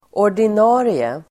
Uttal: [år_din'a:rie]